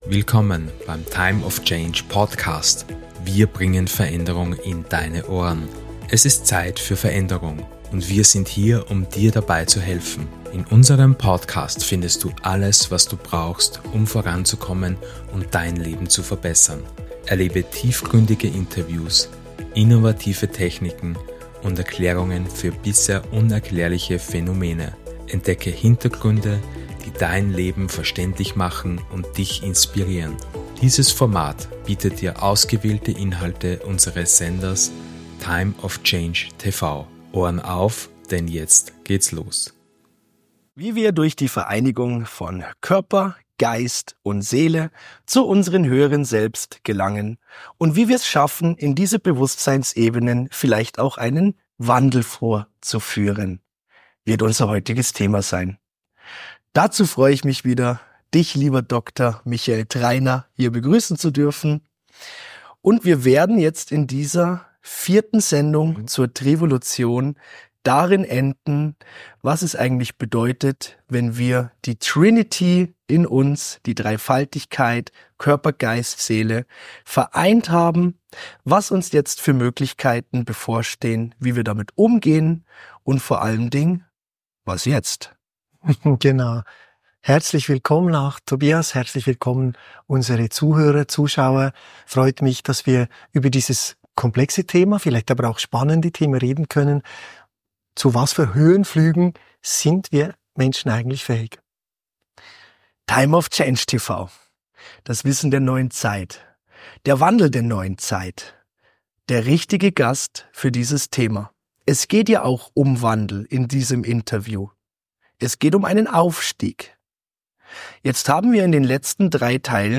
In diesem Interview werden auch kontroverse Themen wie die Existenz außerirdischer Wesen und deren möglicher Einfluss auf unser Leben angesprochen.